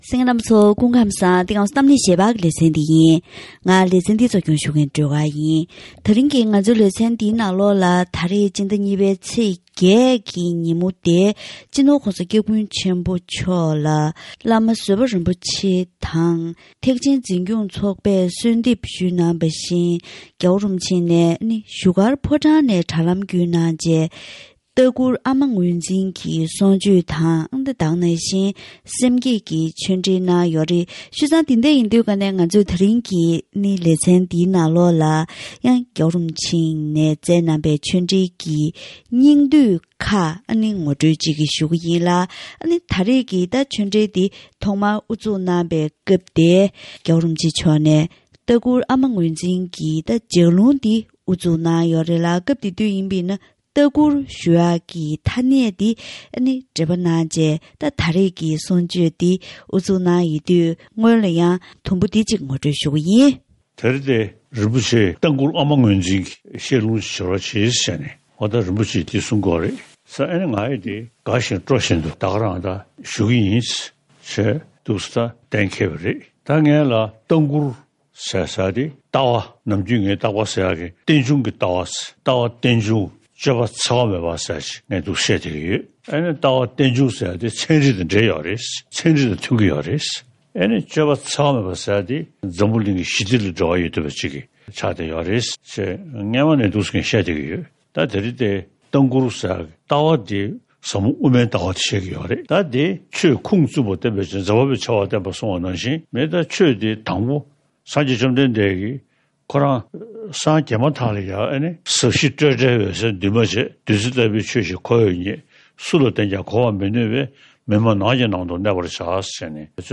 ཕྱི་ཟླ་༢ཚེས་༨ཉིན་ལྟ་མགུར་ཨ་མ་ངོས་འཛིན་གྱི་གསུང་ཆོས་སྐབས།